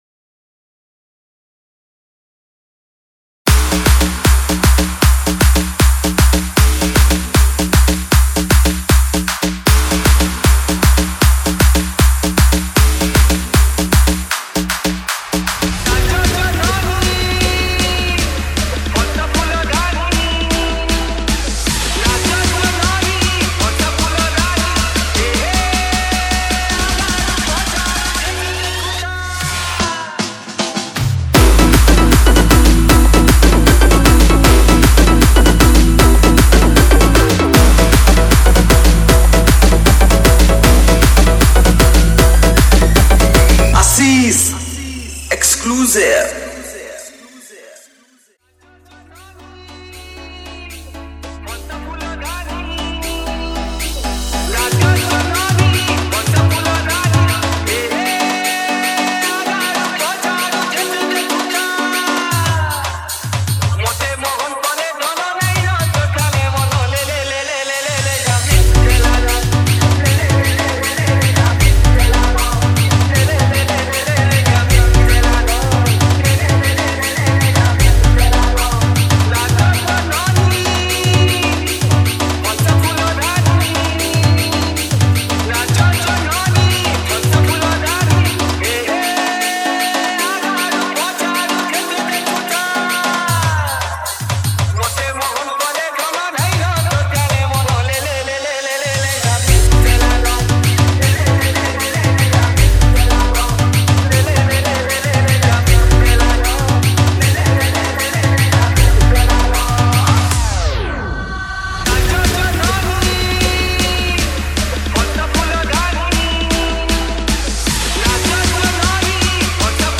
SAMBALPURI ROMANTIC DJ REMIX